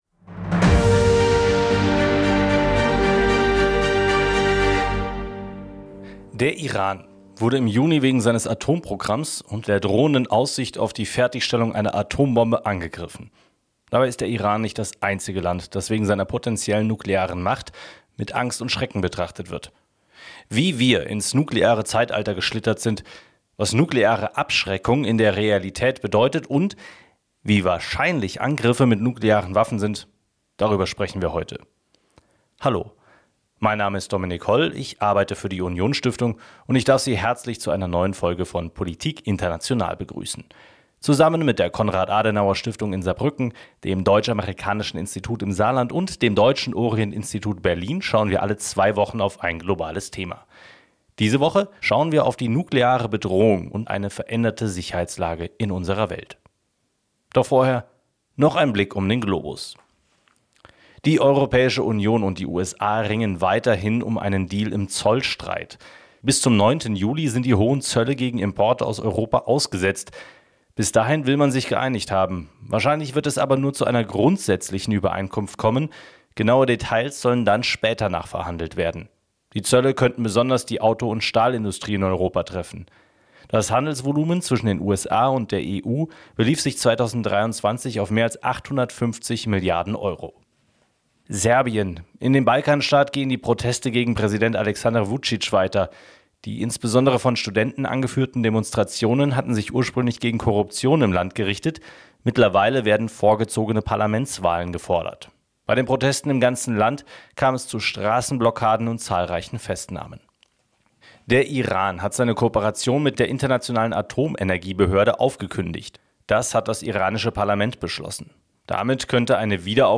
Brauchen wir die USA, oder können uns auch die Franzosen und Briten mit ihrem nuklearen Arsenal schützen? Und: Was heißt nukleare Abschreckung in der politischen Realität wirklich? Ein Gespräch über "die Bombe" und was sie in der aktuellen globalen Situation für unsere Sicherheit bedeutet.